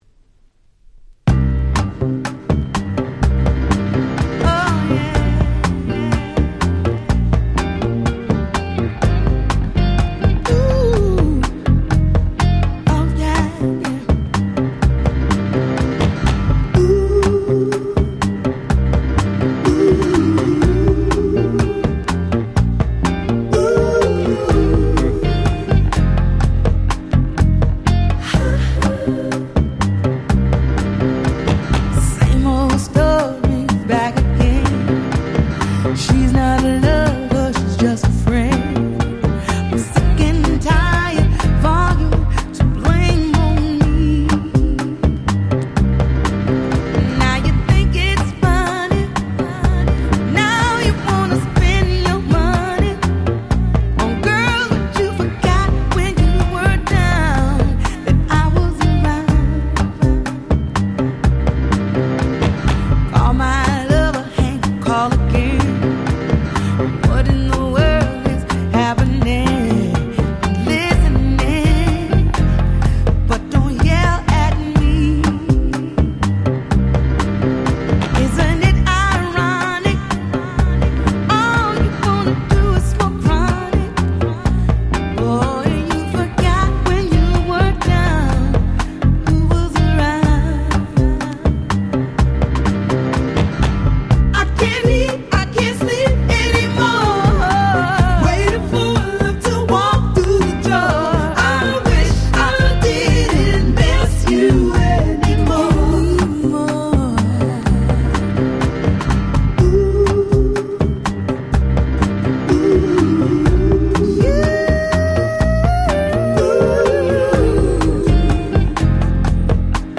01' Very Nice Soul / R&B !!